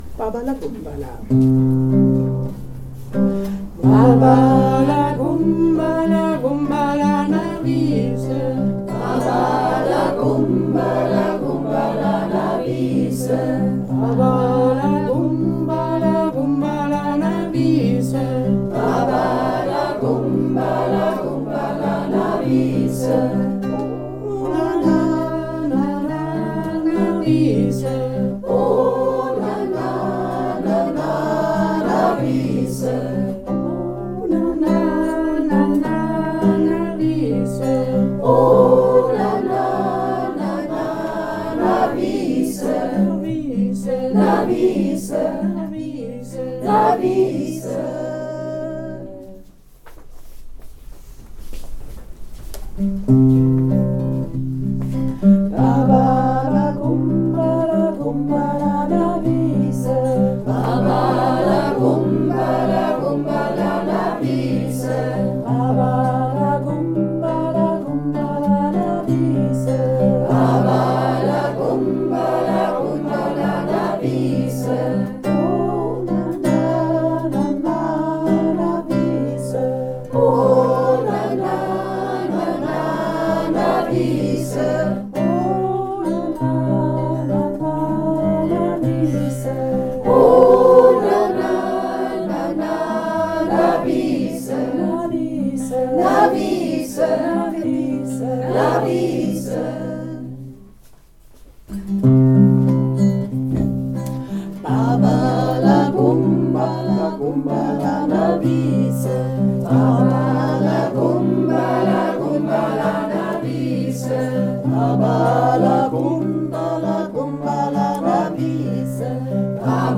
2. Liederwerkstatt Juli 2024
Mehrstimmig, Kanons mit exzelenter Gitarrenbegleitung.
Alle Lieder und Stimmen werden aufgenommen und zur Verfügung gestellt.